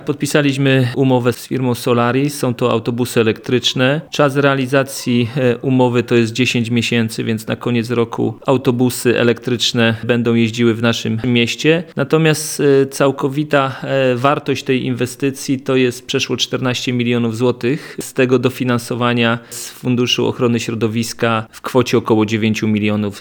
Mówi prezydent Mielca Jacek Wiśniewski.